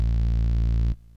Bass (4).wav